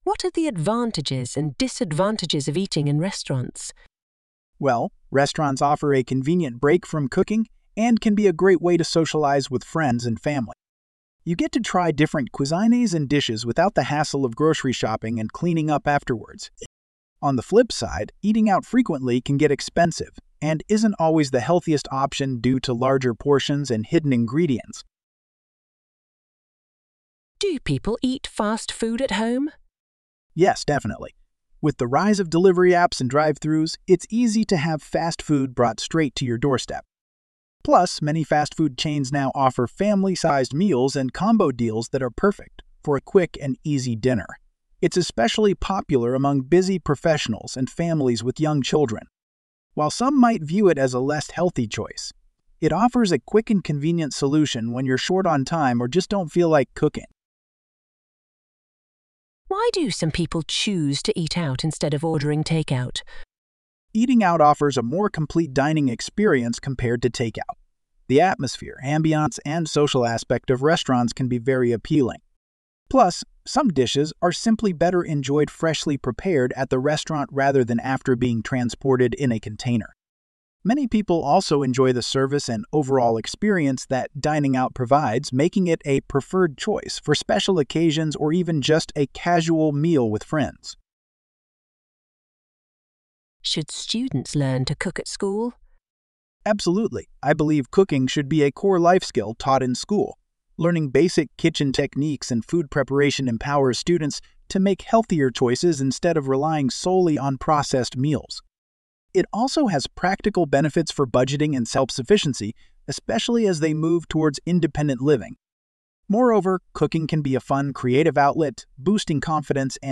Trong bài viết này, Mc IELTS chia sẻ câu trả lời mẫu band 8.0+ từ cựu giám khảo IELTS, kèm theo các câu hỏi mở rộng kèm theo các câu hỏi mở rộng và bản audio từ giáo viên bản xứ để bạn luyện phát âm, ngữ điệu và tốc độ nói tự nhiên.